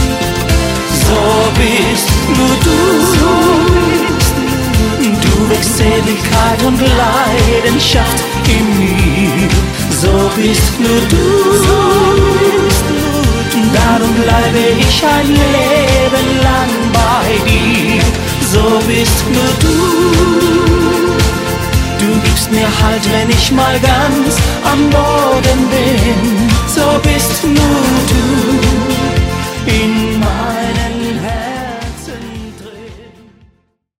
Die Schlagerband mit Herz für jeden Anlass